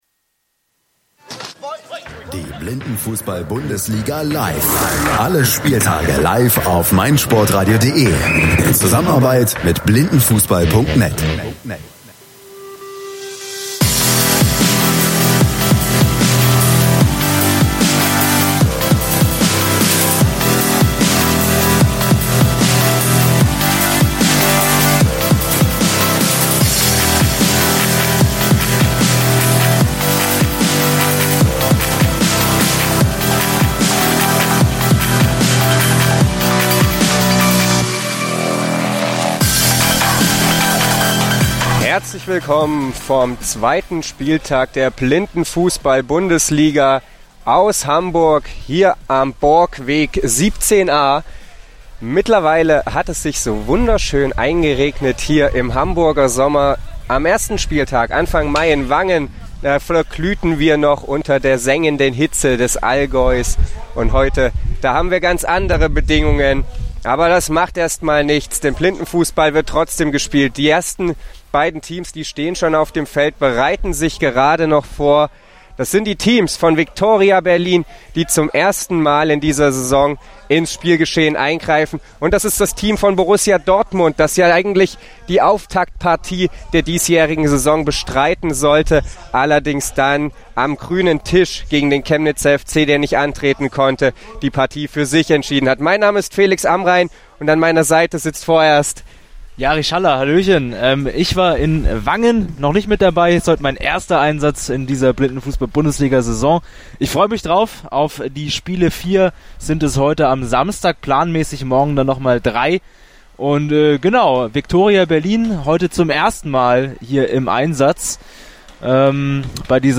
das Spielgeschehen in Hamburg live beschrieben